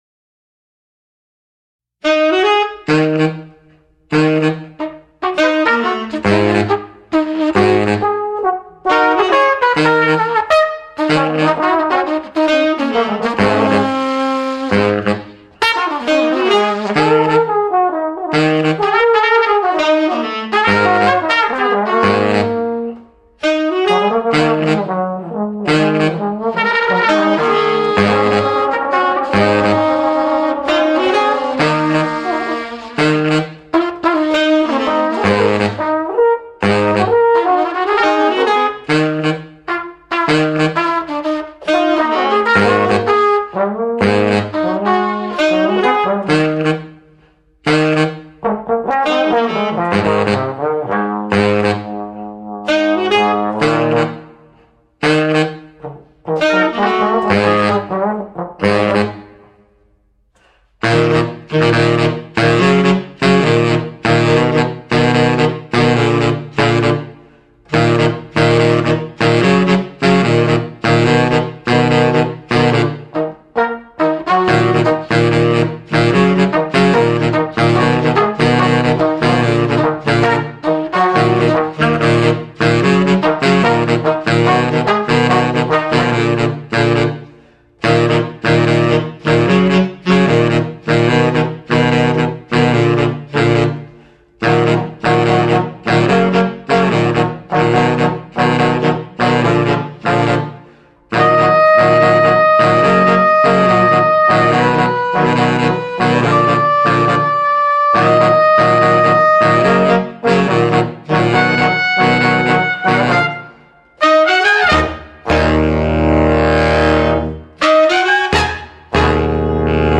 tpt
tbn
tenor sax
bari sax